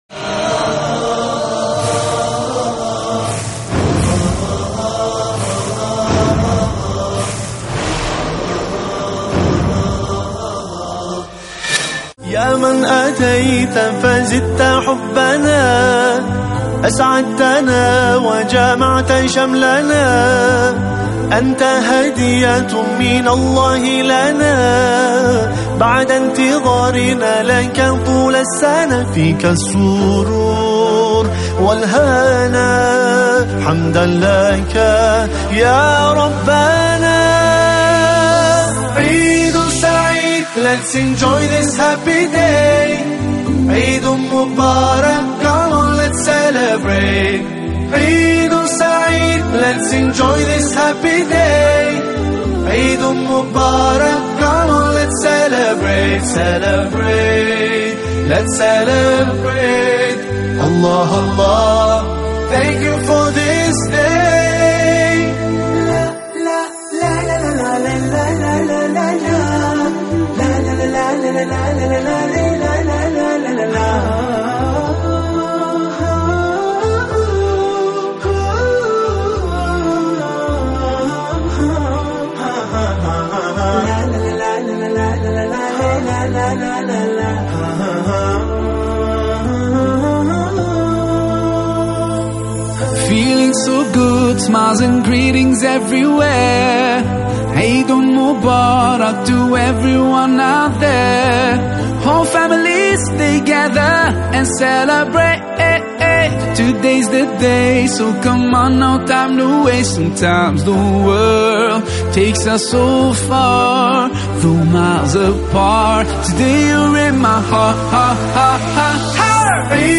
خطبة عيد الأضحى 1437 هـ بجديلة بالمنصورة